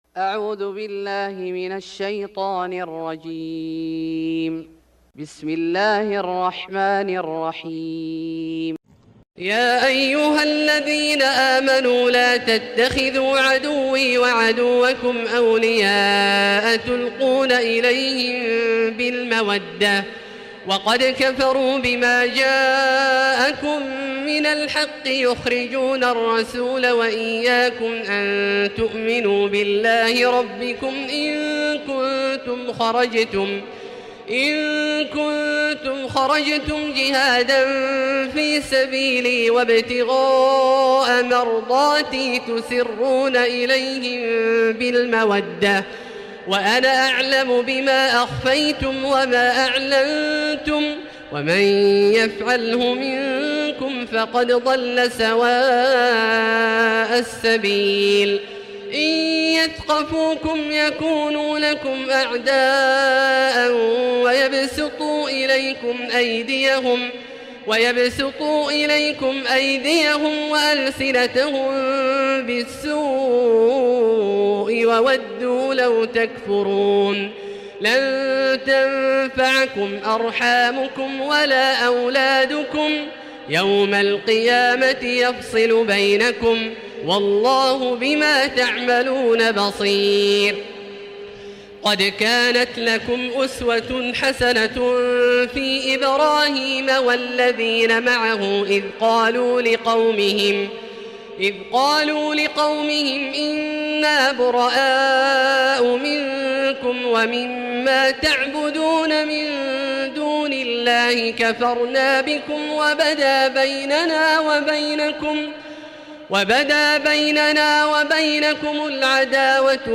سورة الممتحنة Surat Al-Mumtahanah > مصحف الشيخ عبدالله الجهني من الحرم المكي > المصحف - تلاوات الحرمين